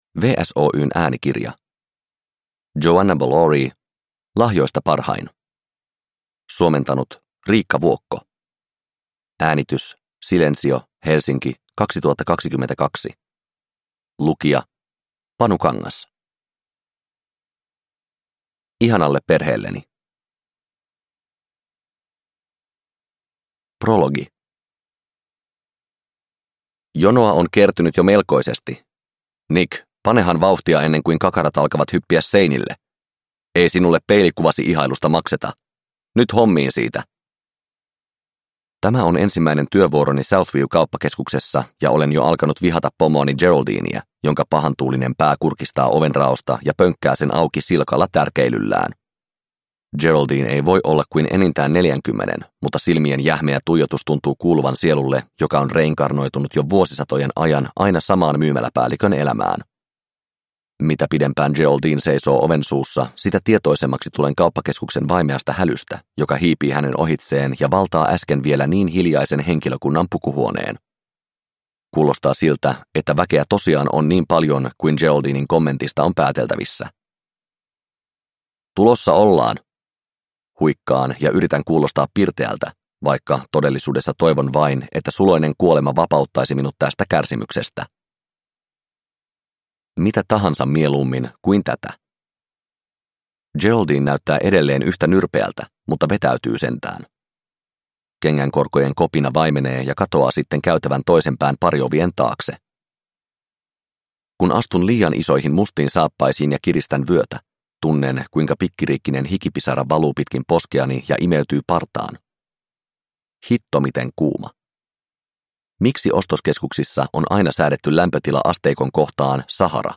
Lahjoista parhain – Ljudbok – Laddas ner